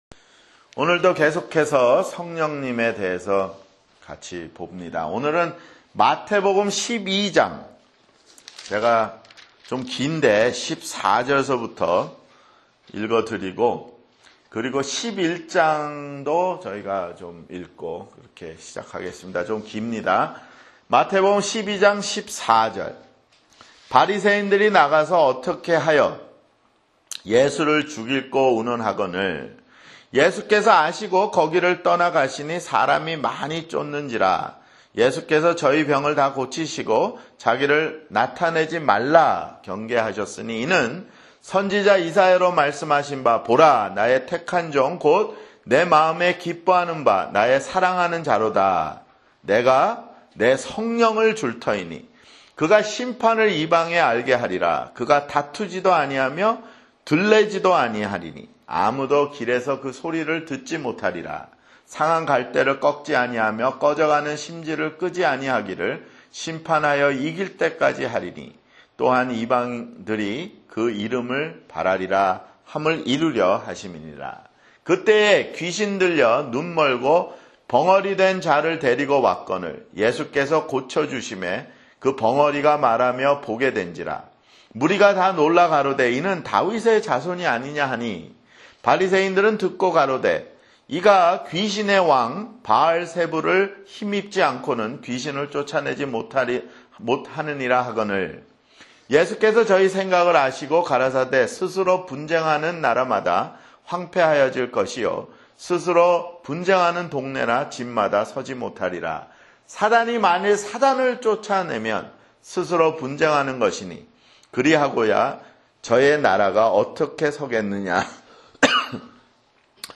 [성경공부] 성령님 (7)